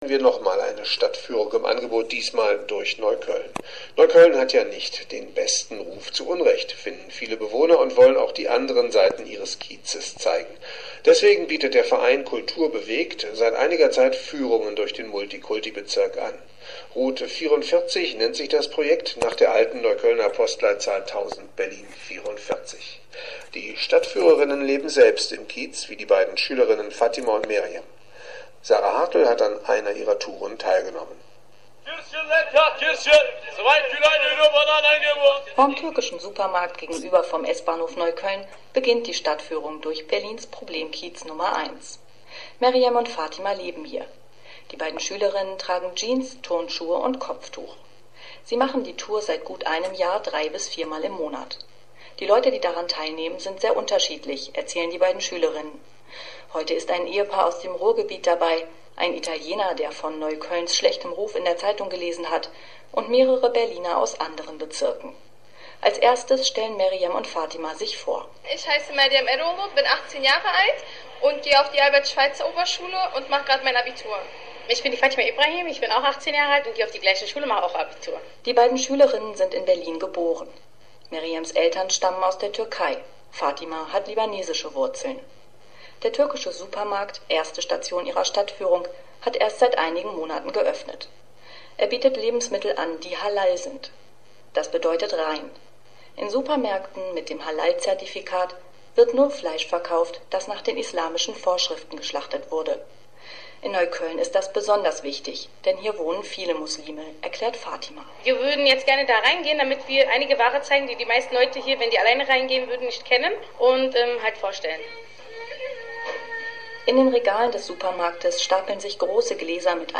Reportage über die Tour "Ein internationales Dorf"